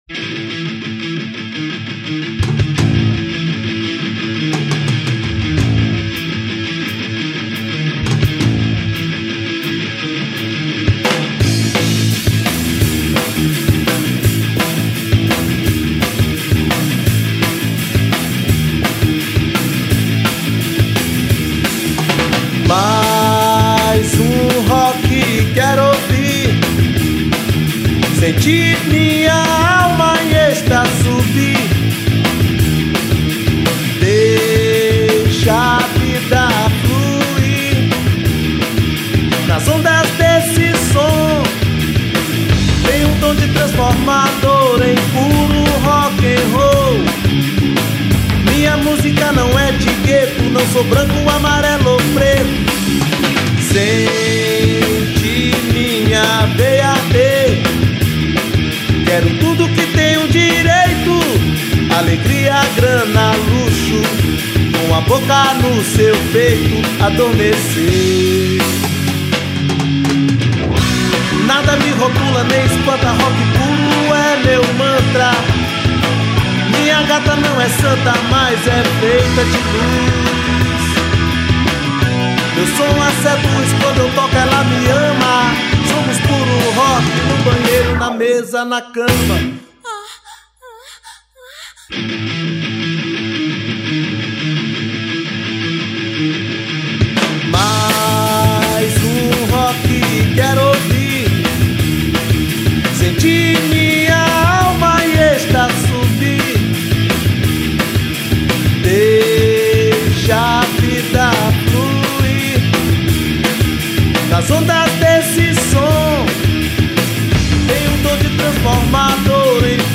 1345   03:15:00   Faixa:     Rock Nacional